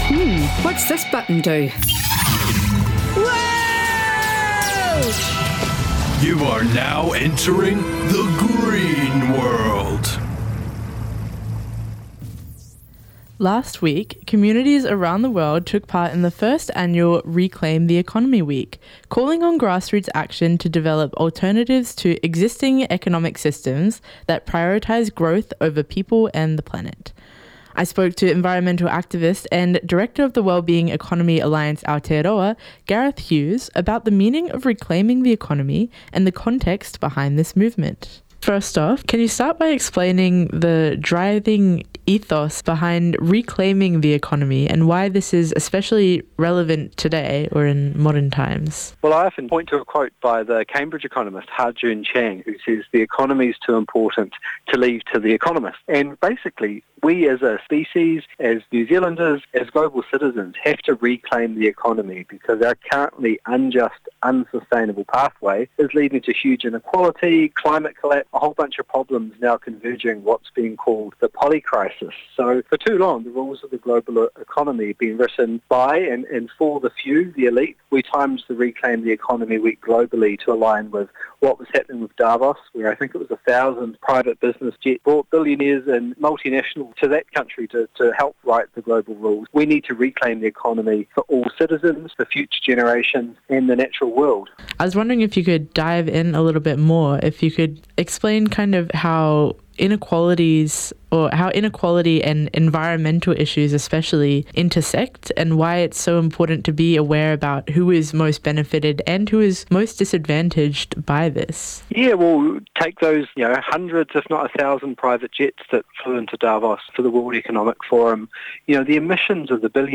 spoke to environmental activist and Director of the Wellbeing Economy Alliance Aotearoa Gareth Hughes about the meaning of ‘reclaiming the economy’ and the context behind this movement.